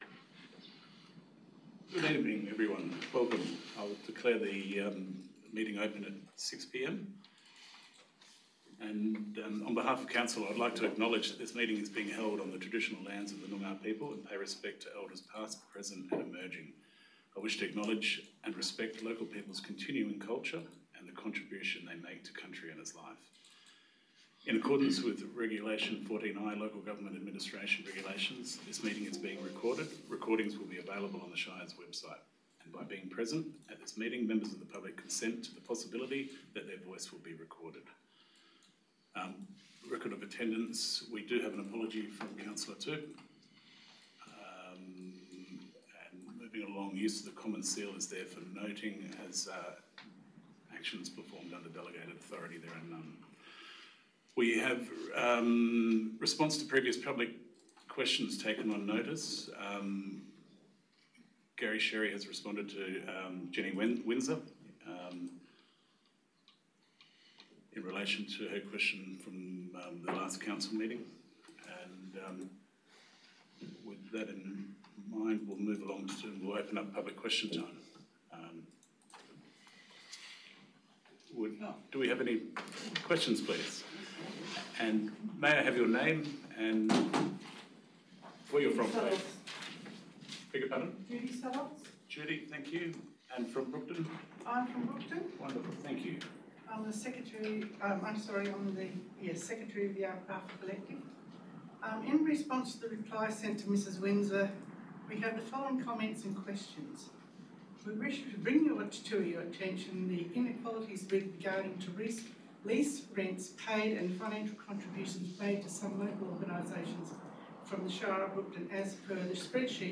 16 April 2026 - Ordinary Meeting of Council » Shire of Brookton